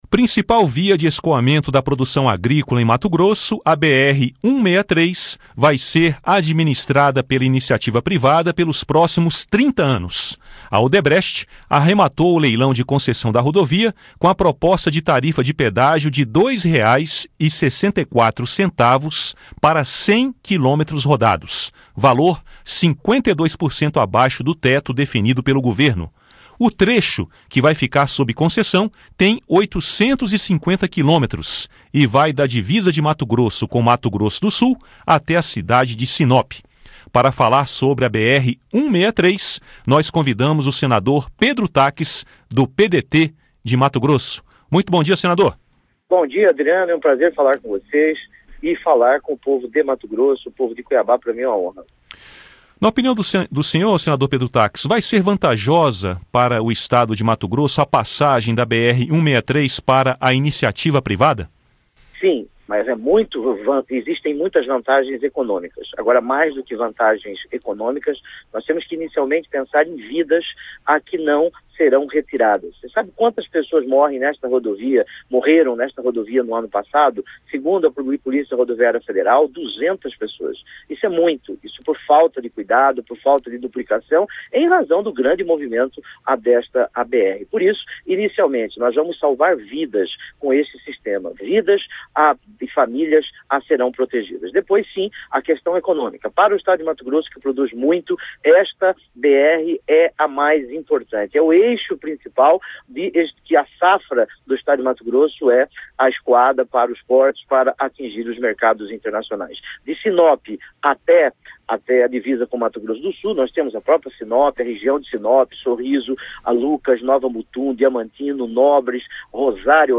Entrevista com o senador Pedro Taques (PDT-MT).